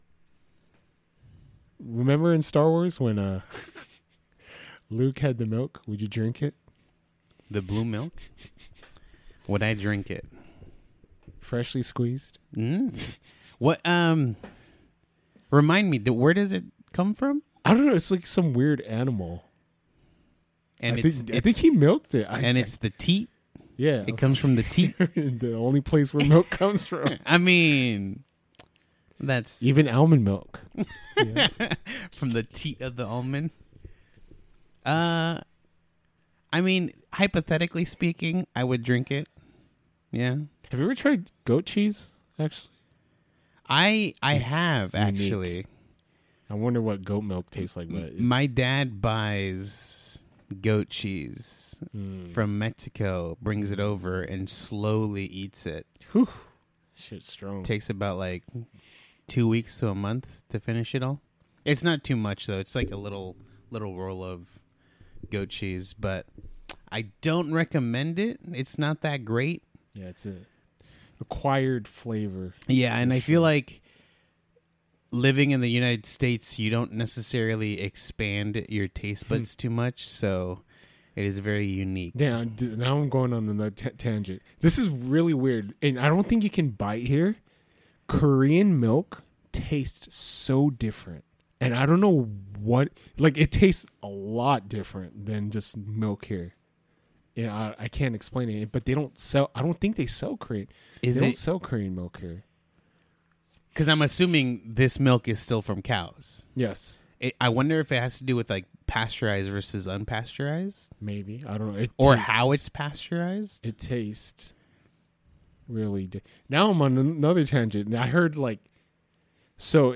WARNING - This episode has some weird audio artifacting/glitching.